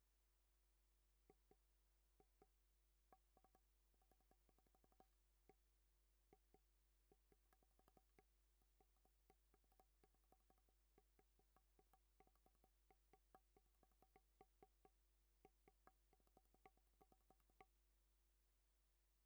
ce que je veux détecter va de la frappe à la main au léger tapotement de doigt à travers un cm de bois plein, à peu près
j'ai fait un enregistrement avec 1MΩ comme résistance variable, c'est ce qui fonctionne le mieux pour ce montage
j'ai un signal audio très faible
je pense que le gros buzz 50Hz vient plutot de l'alim electrique donc de la carte son
reste que j'ai un peu de bruit, mais je pense qu'à ce niveau c'est inévitable non ?
piezo_record.wav